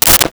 Sword Whip 02
Sword Whip 02.wav